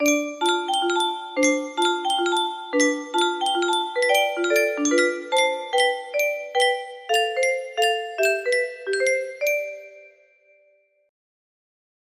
Levin Music Box 2 music box melody